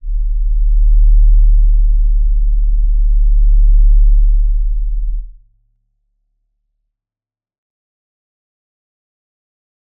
G_Crystal-D1-f.wav